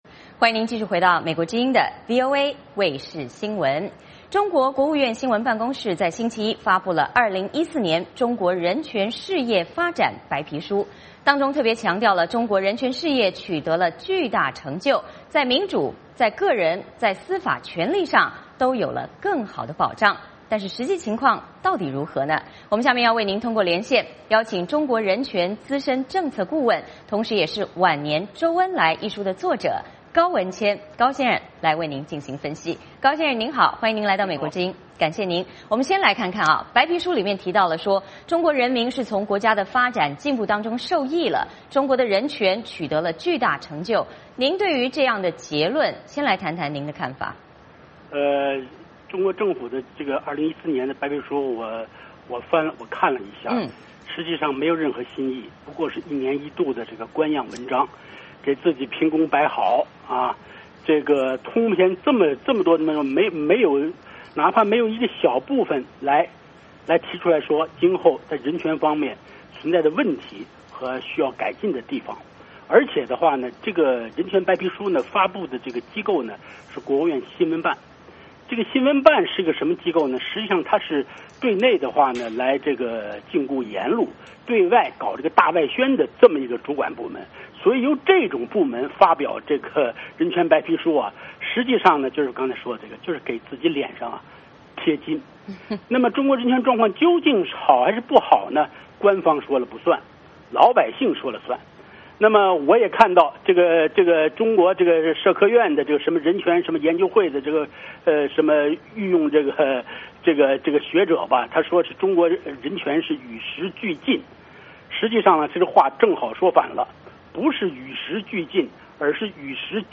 VOA连线：中国发布人权白皮书，称人权事业取得新成就